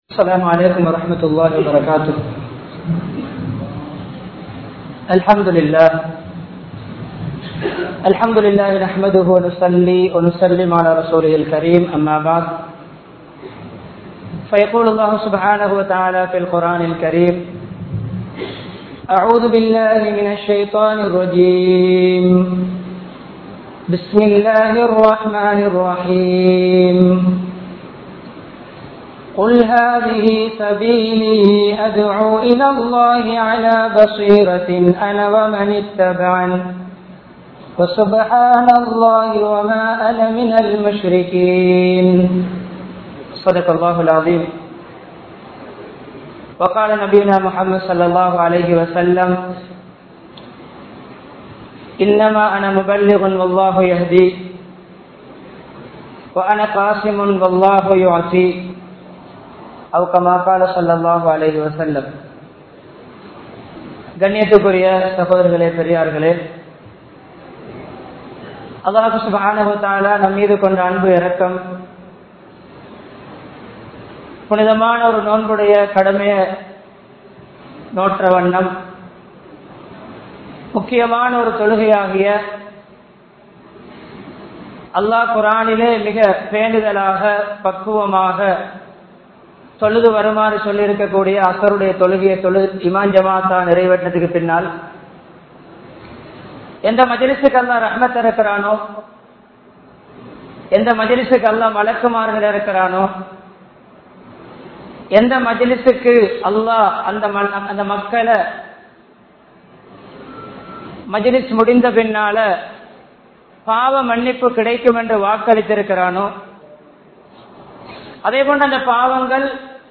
Colombo 11, Samman Kottu Jumua Masjith (Red Masjith)